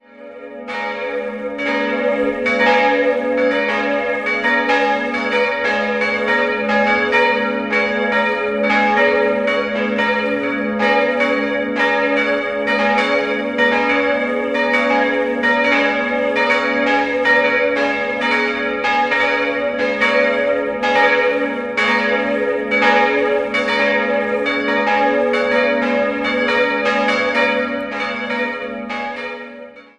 Jahrhundert, während das restliche Gotteshaus im Jahr 1912 im neubarocken Still errichtet wurde. 3-stimmiges Gloria-Geläute: as'-b'-des'' Die mittlere Glocke wurde 1922, die anderen 1950 von Karl Hamm in Regensburg gegossen.